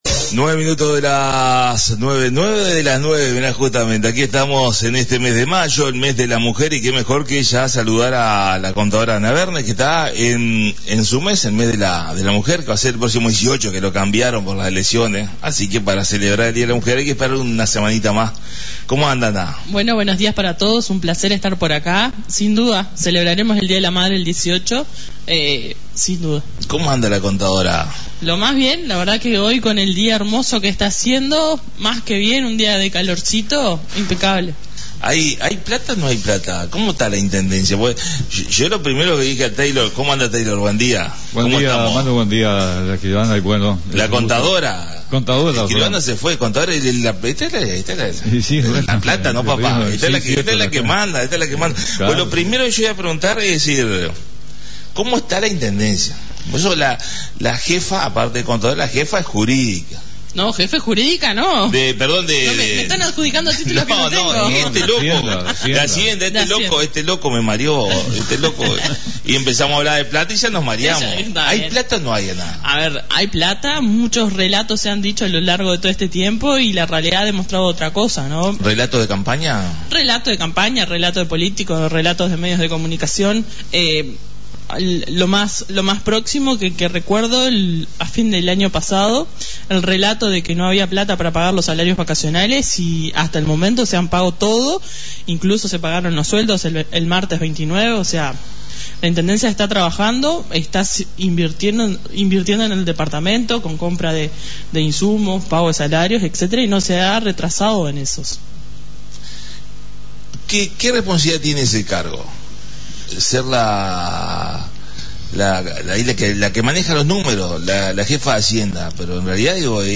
La visita a la Radio